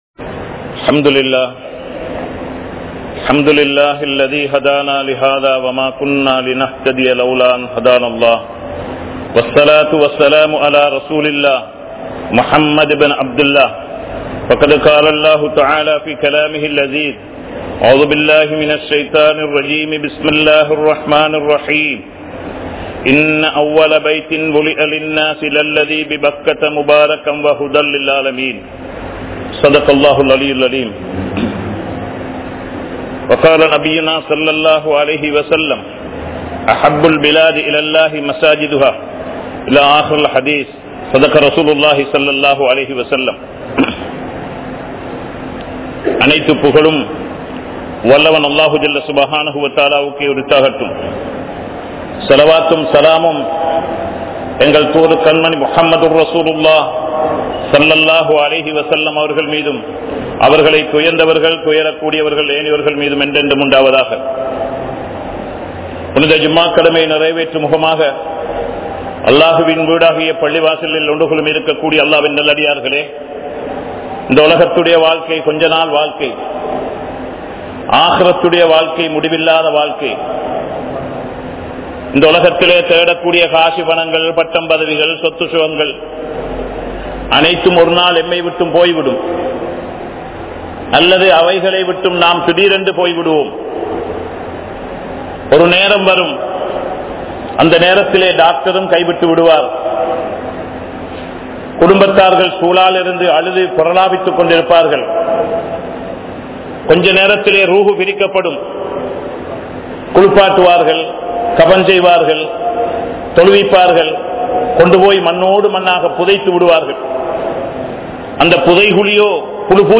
Muslimkale! Ottrumaiyaaha Vaalungal (முஸ்லிம்களே! ஒற்றுமையாக வாழுங்கள்) | Audio Bayans | All Ceylon Muslim Youth Community | Addalaichenai
Aluthgama,Dharga Town, Grand Jumua Masjith